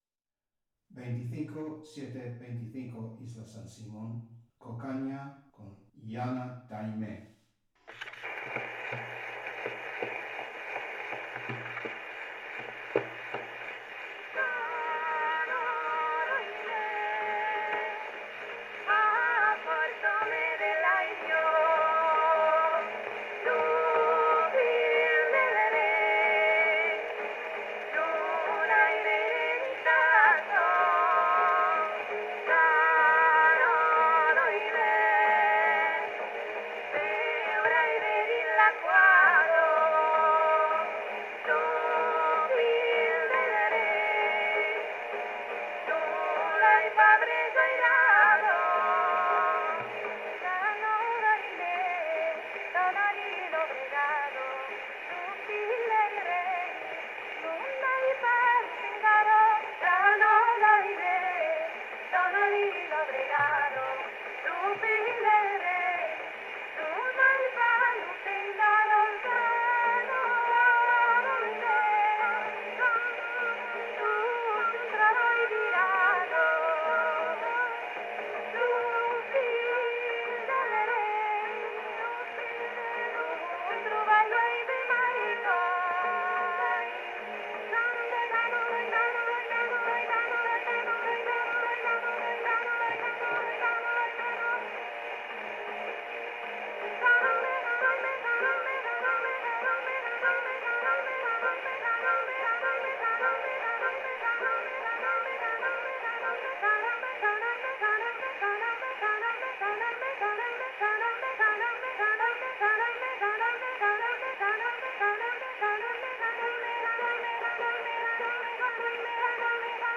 Original sound from the phonographic cylinder:
Recording place: Espazo Cafetería